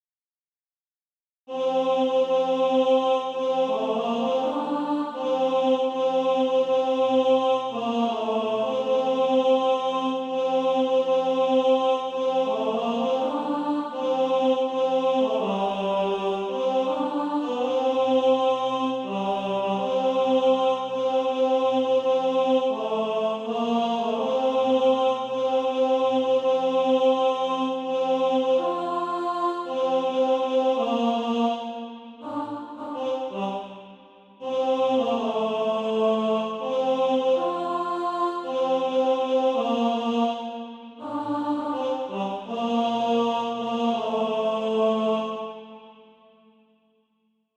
(SATB) Author
Tenor Track.
Practice then with the Chord quietly in the background.